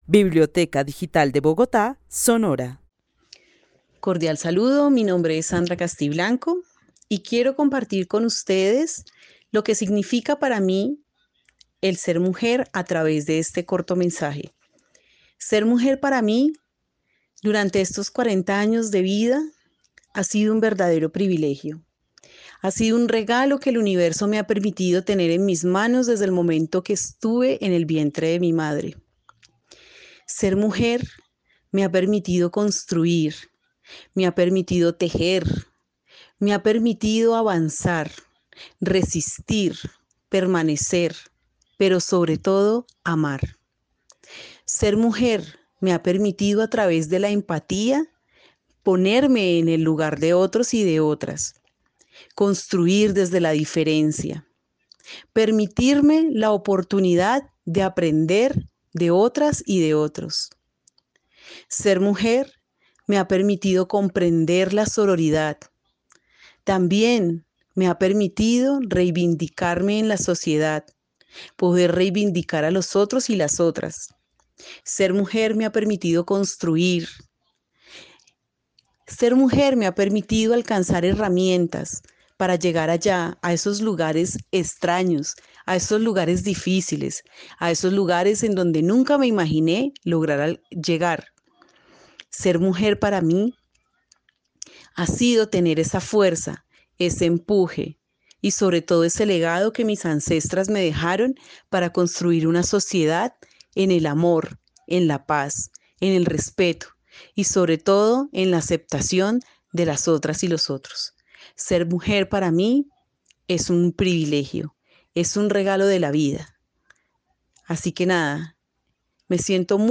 Narrativas sonoras de mujeres